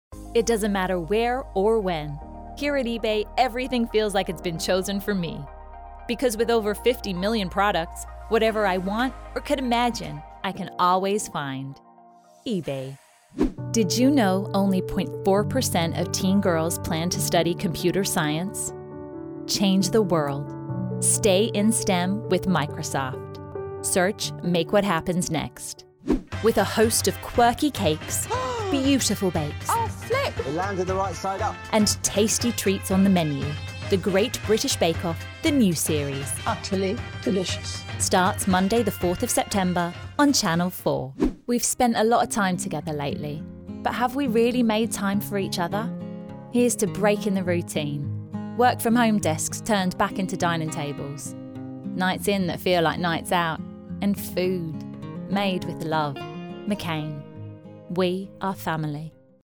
Australian / English. Actress, contemporary, young, warm, energetic.
Australian and English Commercials